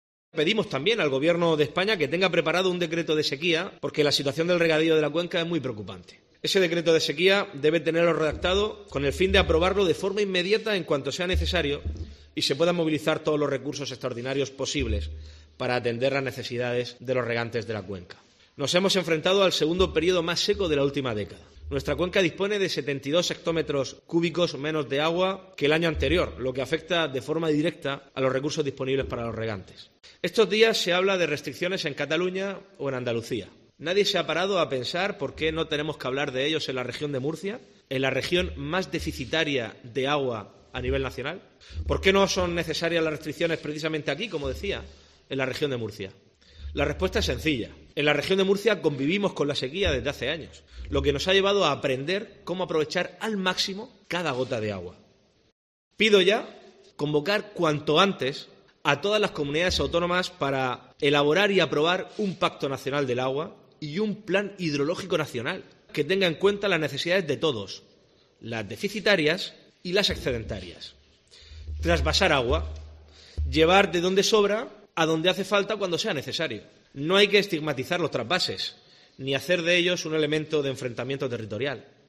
López Miras ha hecho estas declaraciones en una rueda de prensa celebrada en el Palacio de San Esteban, tras participar en una reunión con representantes de las organizaciones agrarias y de los regantes en la que se ha analizado la situación de los recursos hídricos.